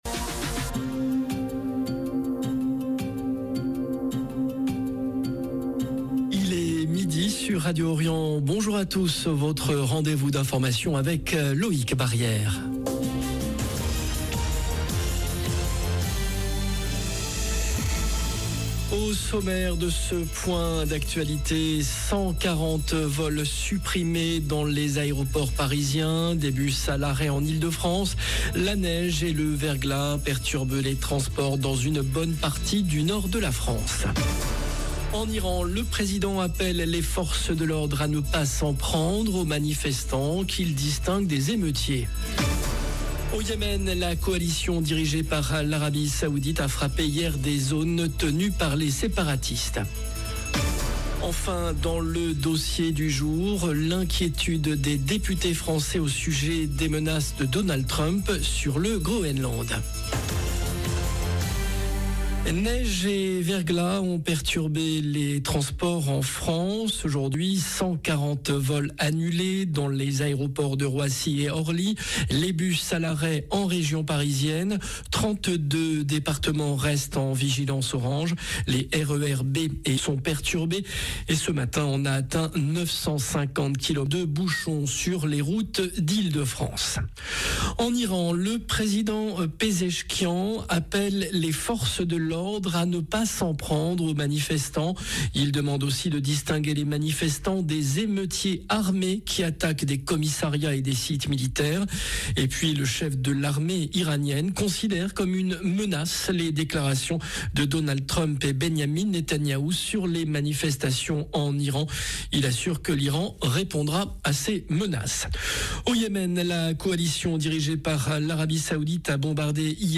Journal de midi